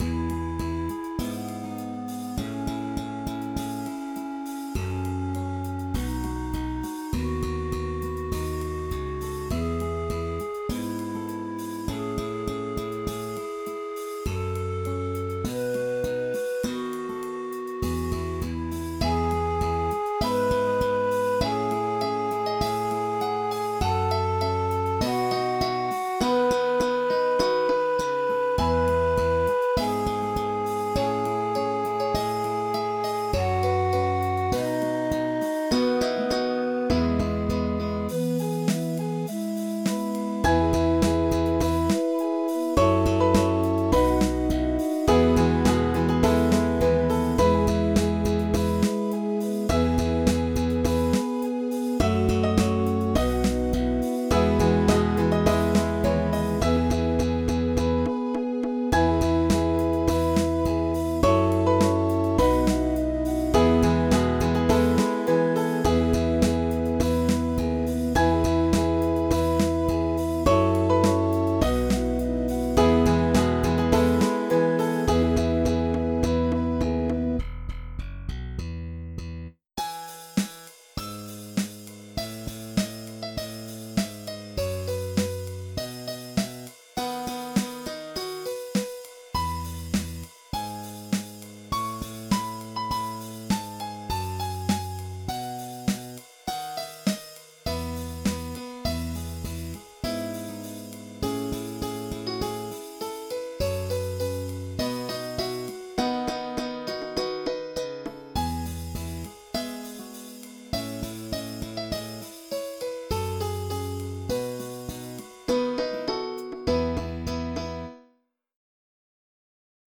After the double chorus, we are going to add a single measure with only bass.